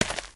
sounds / material / human / step / earth01gr.ogg